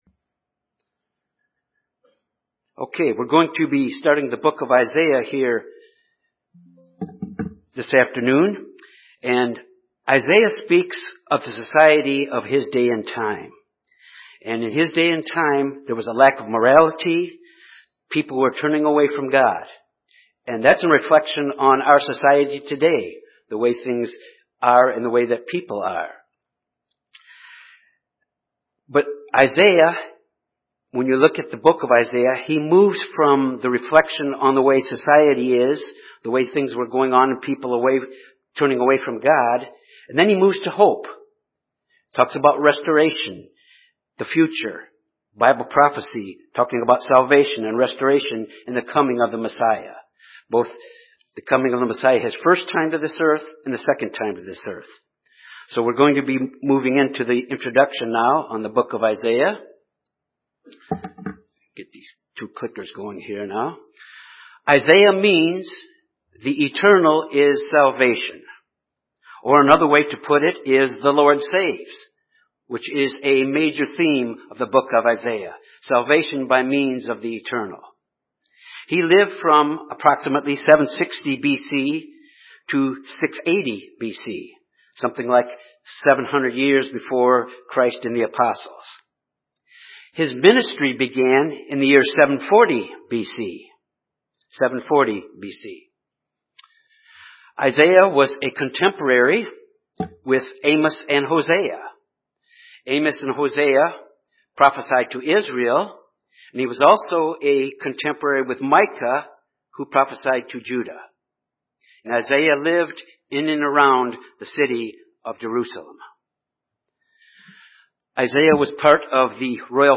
Bible Study- Isaiah-Introduction and Chapter 1